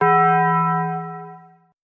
bong.wav